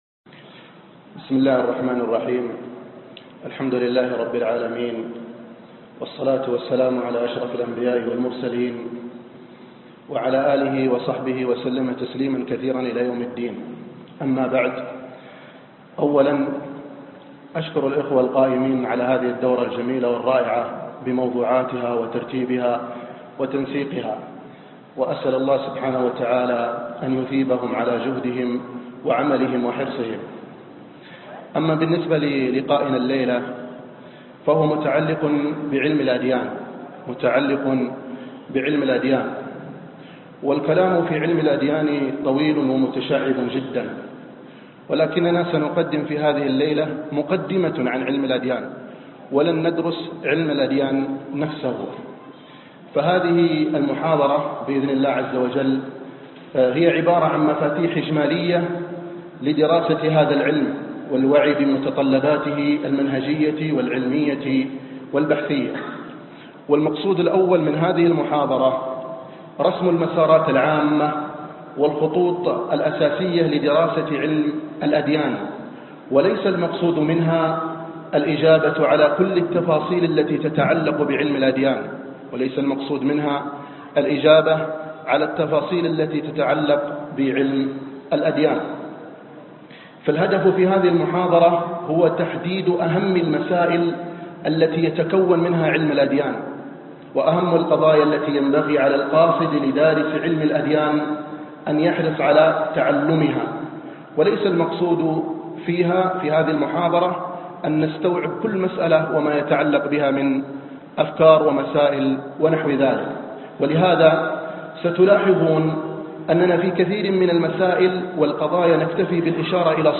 المدخل إلى دراسة الأديان- محاضرة